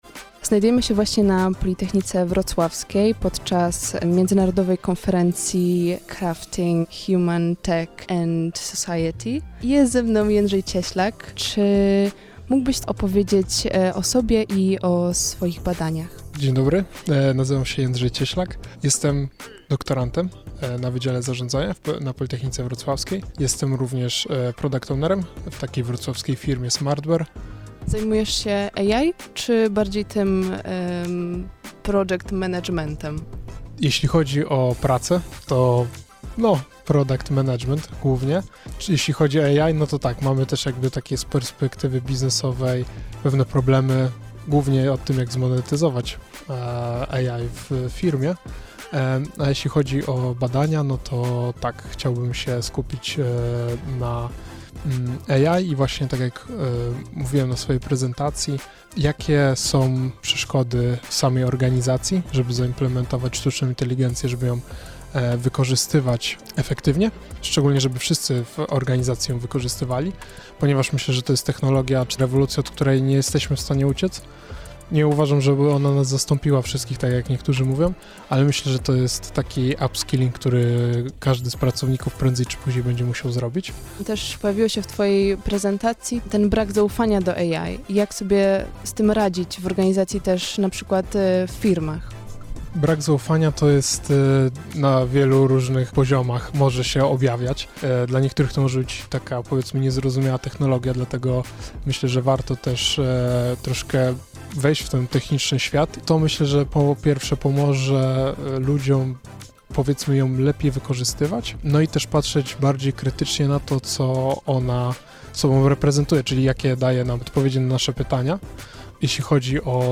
Od 13 do 17 kwietnia na Wydziale Zarządzania odbywała się międzynarodowa konferencja CRAFTing Human-Tech-Society.